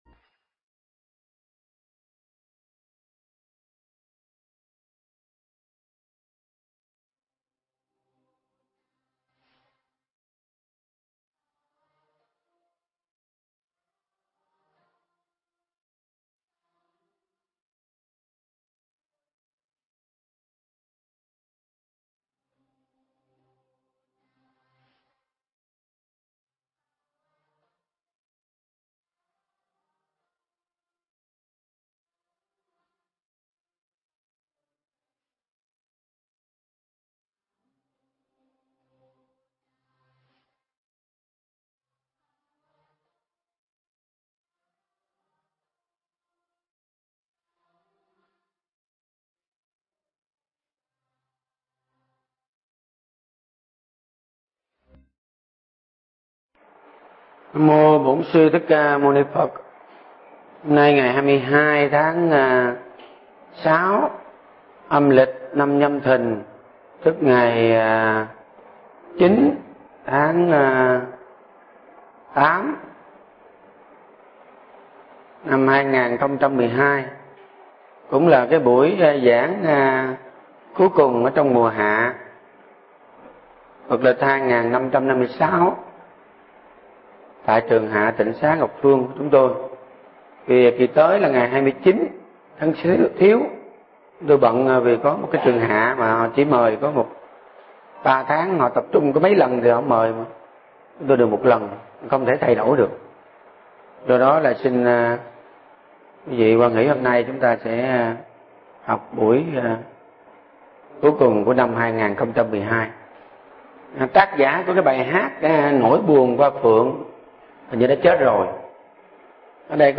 Nghe Mp3 thuyết pháp Thọ Duyên Ái